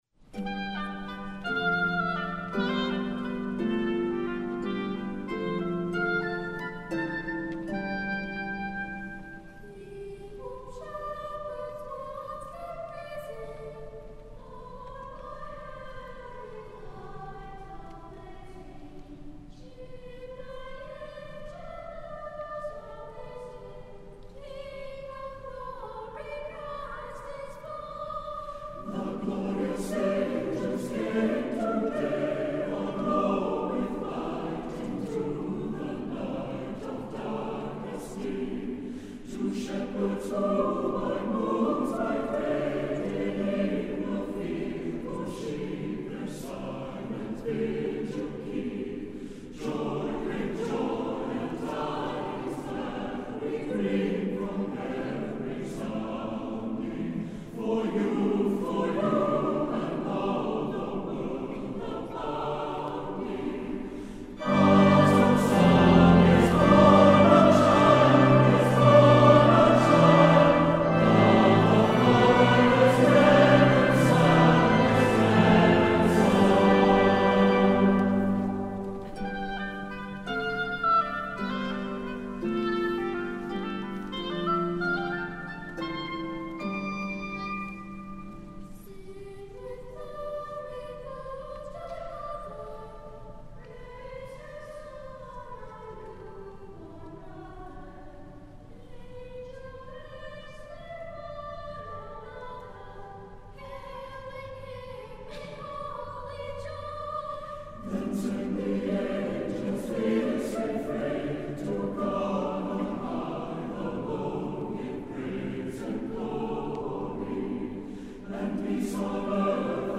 Voicing: SATB and Children's Choir